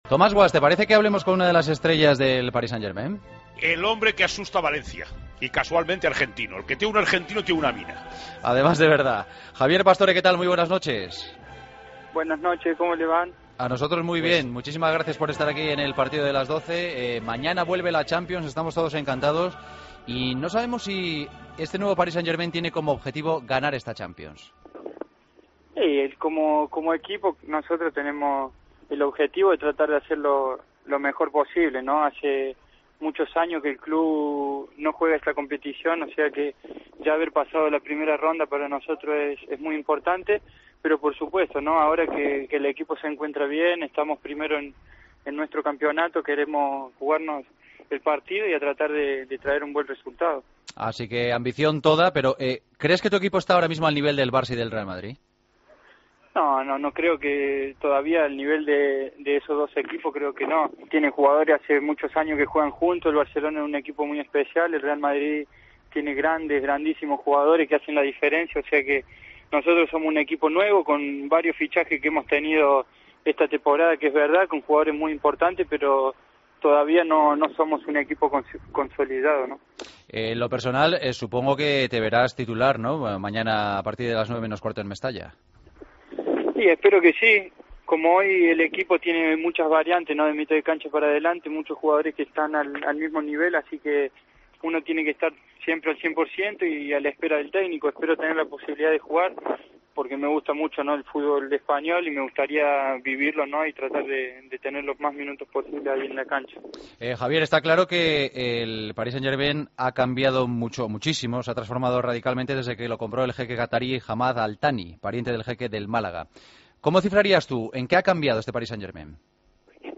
Entrevista a Javier Pastore, en la previa del Valencia-PSG